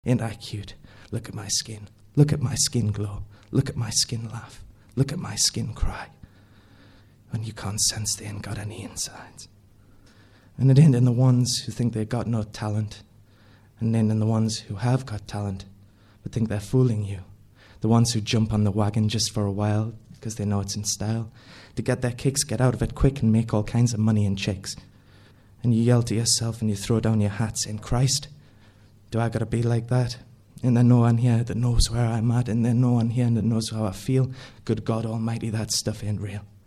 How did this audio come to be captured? Ashington Folk Club - Spotlight 21 July 2005